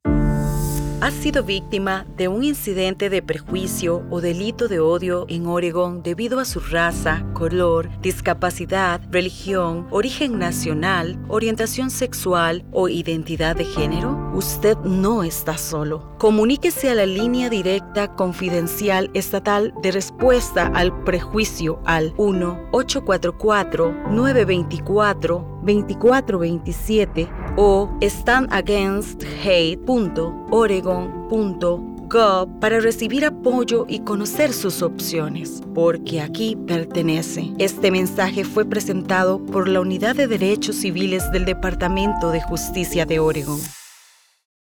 DOJ You Belong. Audio PSA (Spanish – 43 sec)
YOU-BELONG_RADIO_SPANISH_FINAL.wav